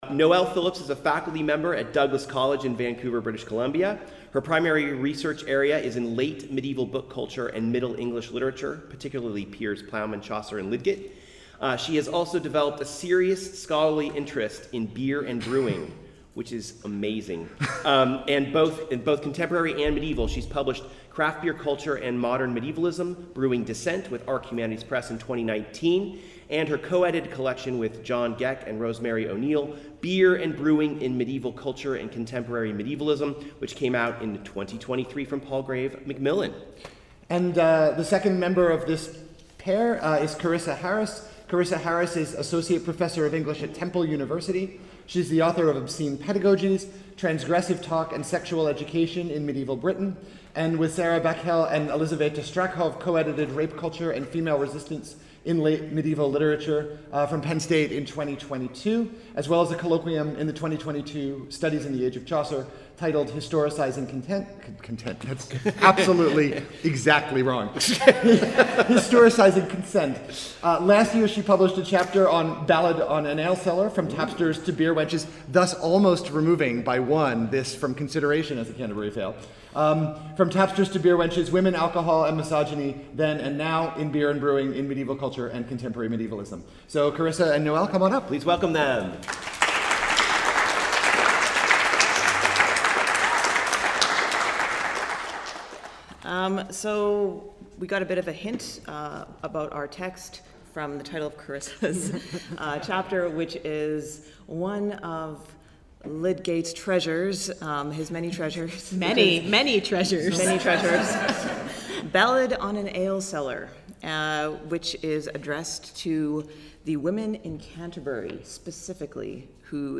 A very special episode live from the International Congress on Medieval Studies